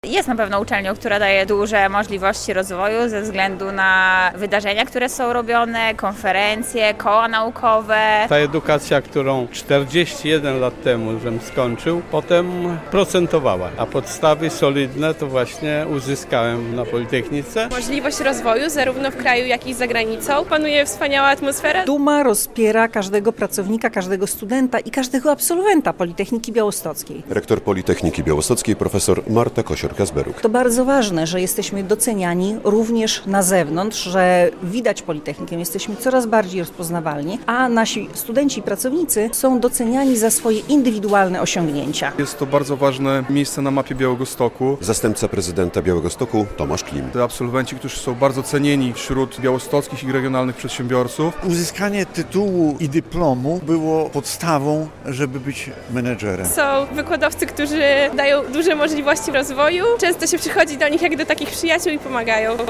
Z tej okazji w auli Wydziału Elektrycznego odbyła się uroczystość, podczas której studenci i absolwenci odebrali wyróżnienia i nagrody, natomiast część pracowników dydaktycznych otrzymało awanse na wyższe stopnie naukowe.